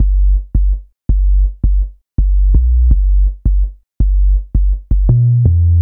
04Subway 165bpm Dm.wav